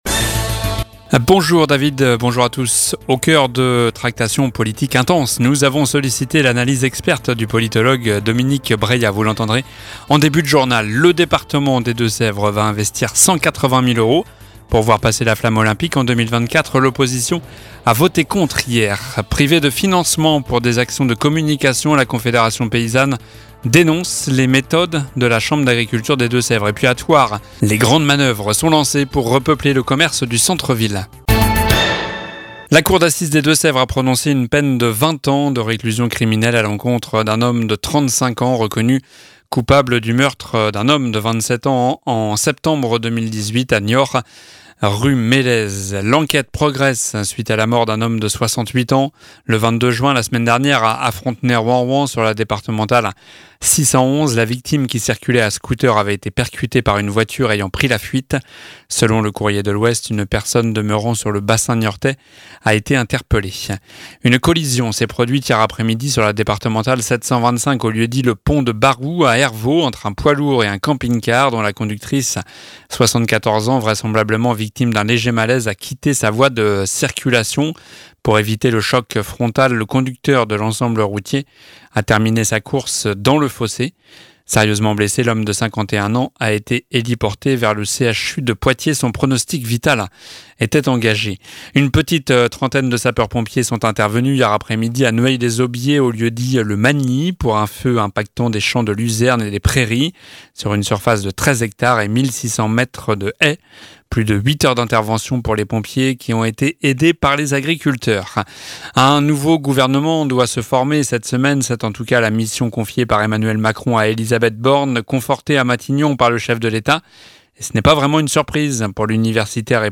Journal du mardi 28 juin (midi)